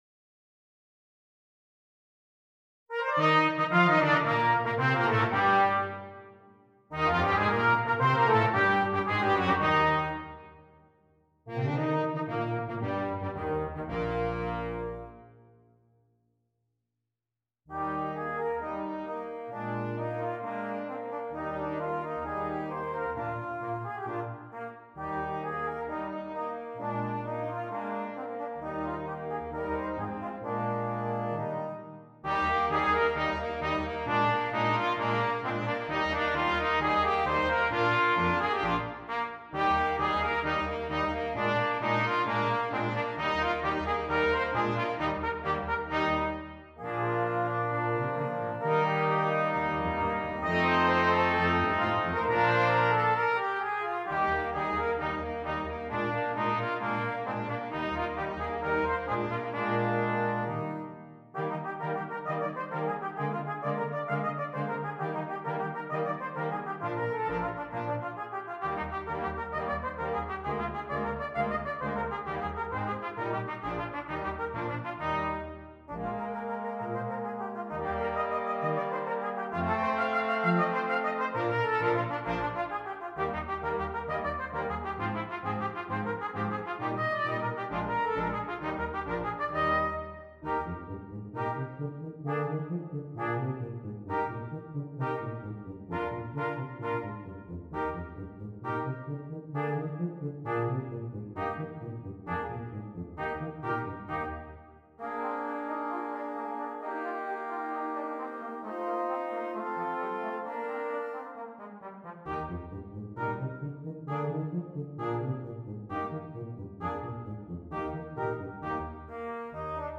Brass Quintet
This is a fun show-off tune for brass quintet.